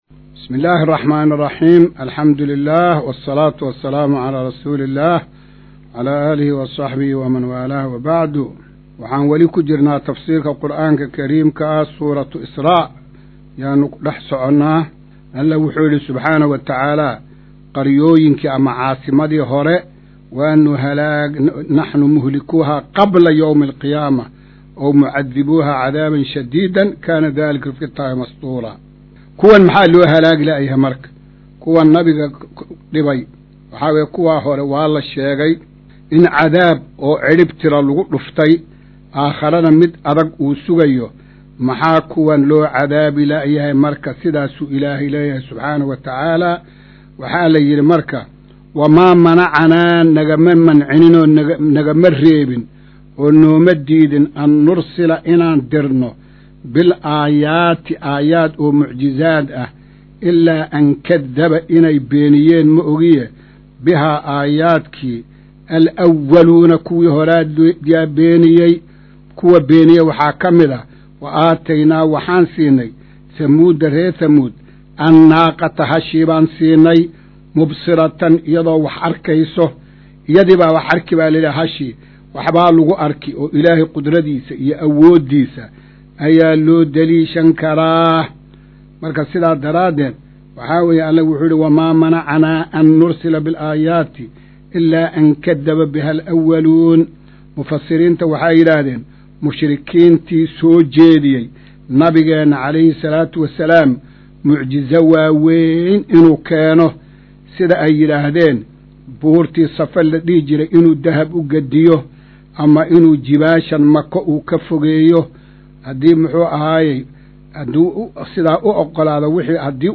Maqal:- Casharka Tafsiirka Qur’aanka Idaacadda Himilo “Darsiga 140aad”